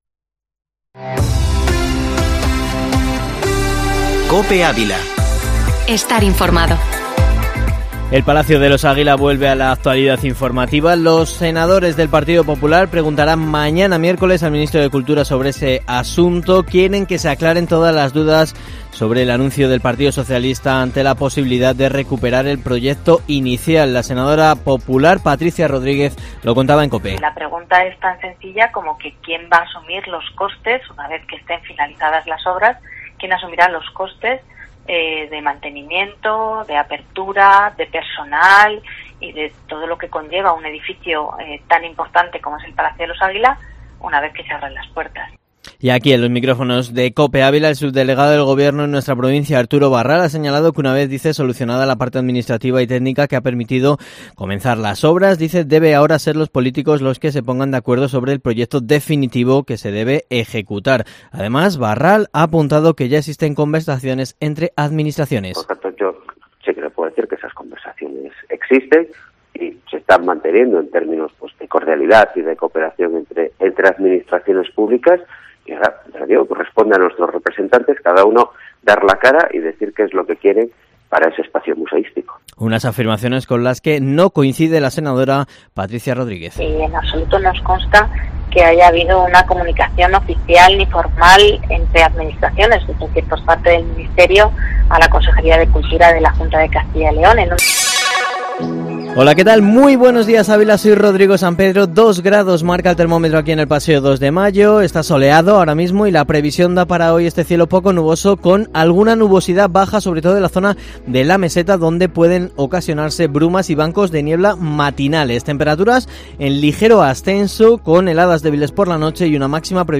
Informativo matinal Herrera en COPE Ávila 16/03/2021